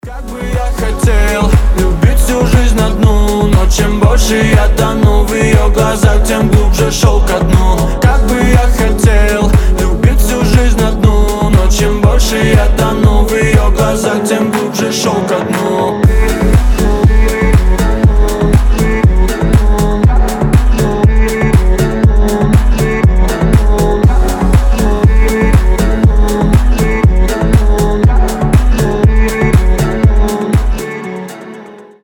• Качество: 320, Stereo
поп
мужской вокал
рэп